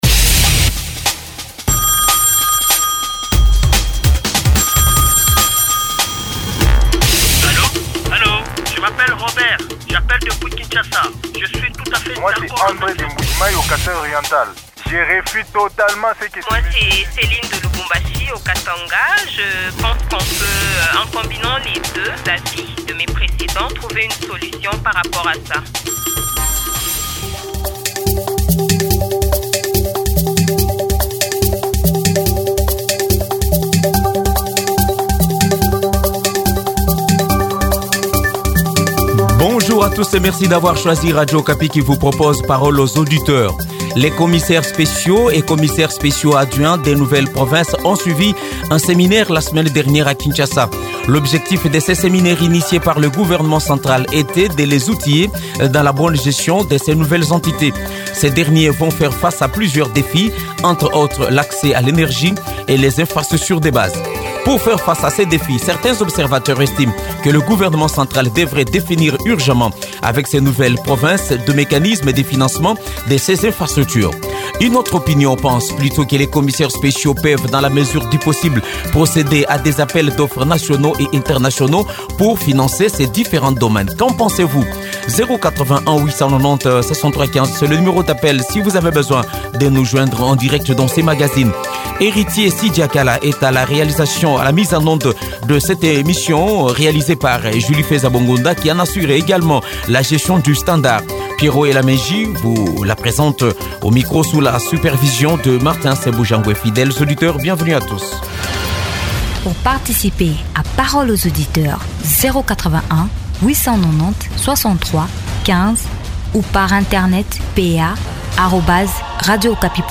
analyste économique.